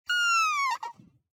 nutria-v1.ogg